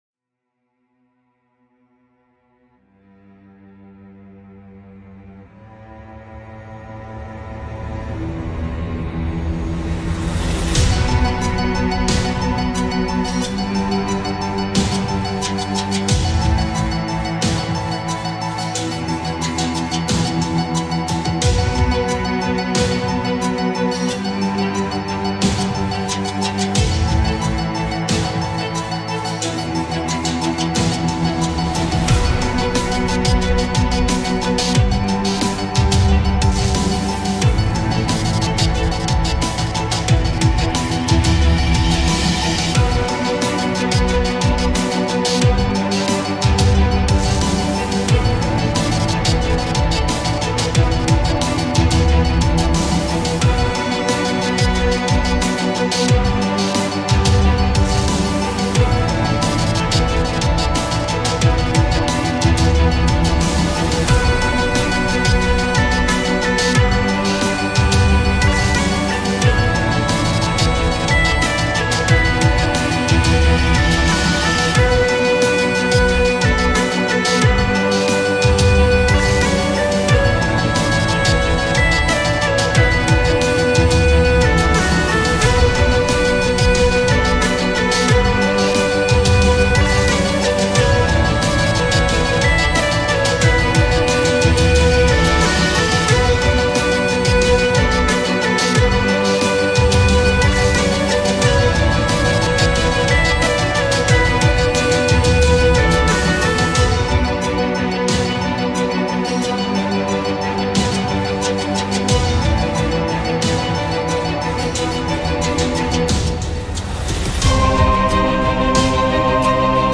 Here's a new epic-orchestral thingy, I hope you'll enjoy!
Best Orchestral Song of 2015 on Reddit!!!